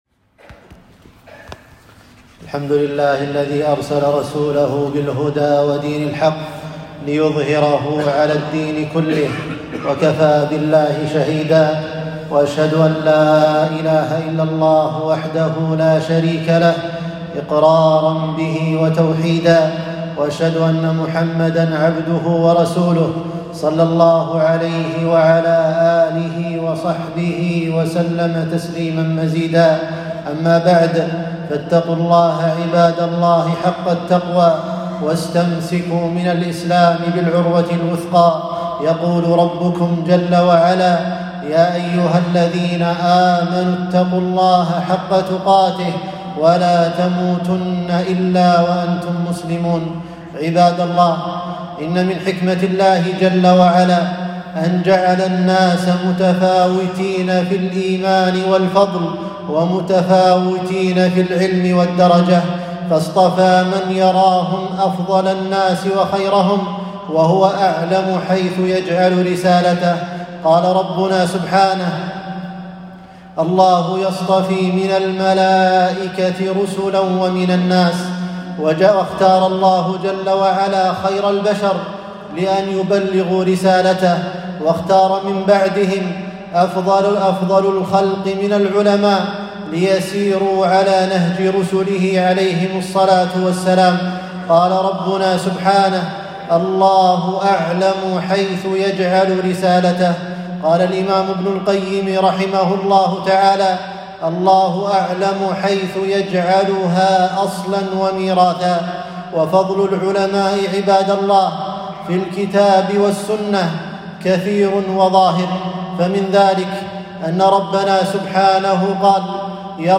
خطبة - فضل العلماء والتحذير من لمزهم